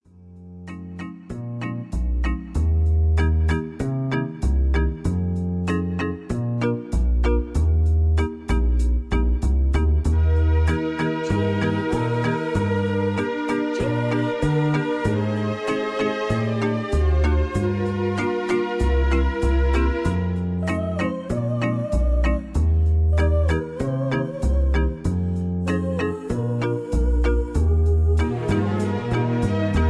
Just Plain & Simply "GREAT MUSIC" (No Lyrics).
mp3 backing tracks